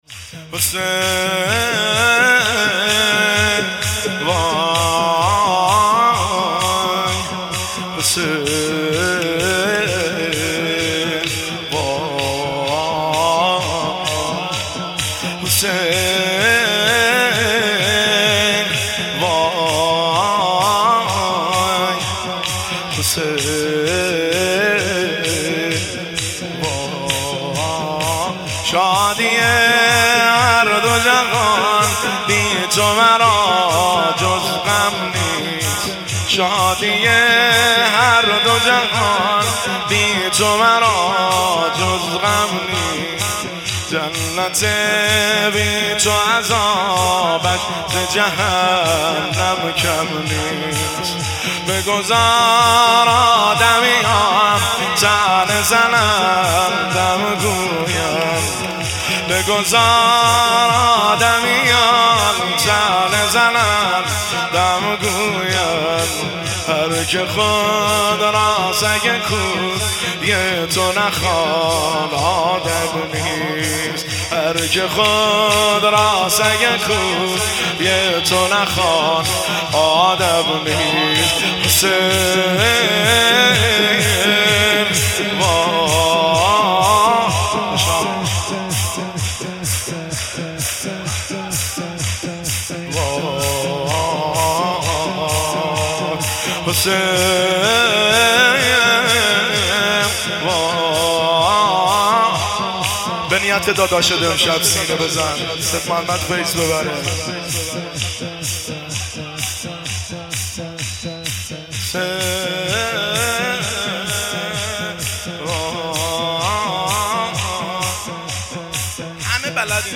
شادی-هر-دو-جهان-شور.mp3